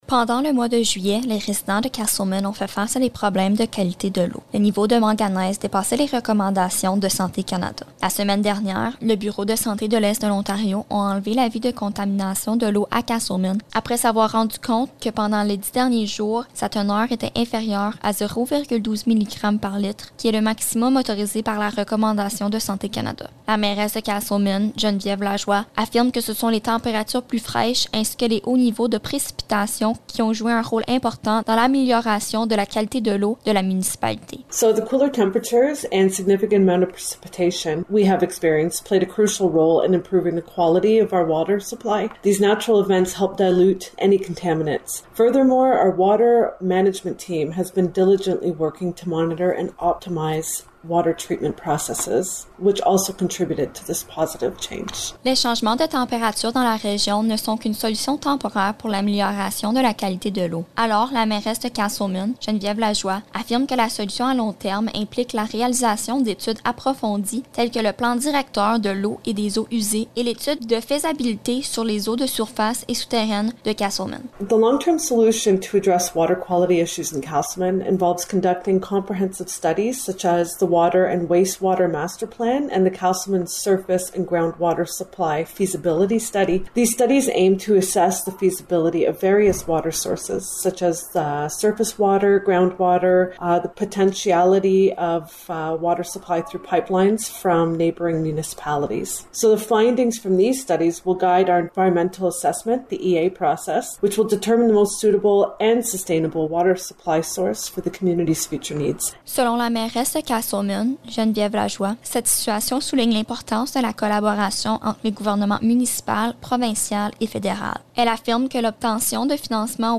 Reportage-eau-de-Casselman-8-aout.mp3